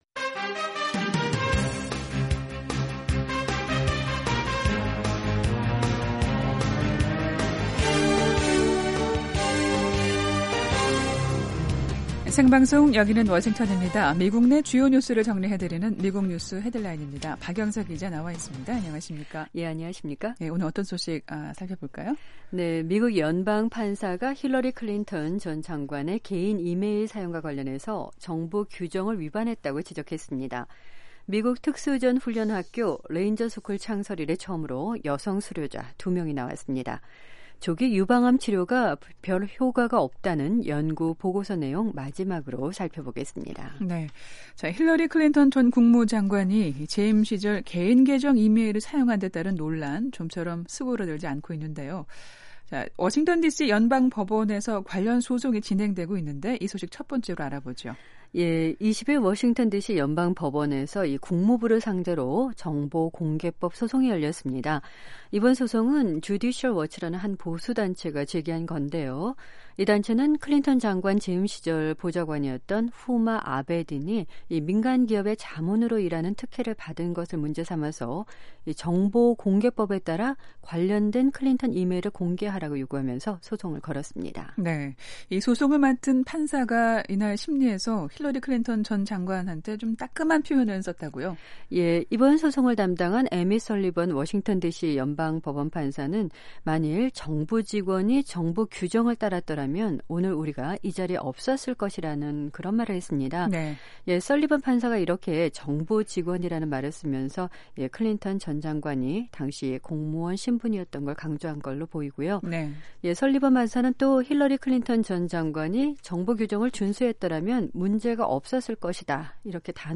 미국 내 주요 뉴스를 정리해 드리는 ‘미국 뉴스 헤드라인’입니다. 미국 연방판사가 힐러리 클린턴 전 장관의 개인 이메일 사용과 관련해 정부의 규정을 위반했다고 지적했습니다.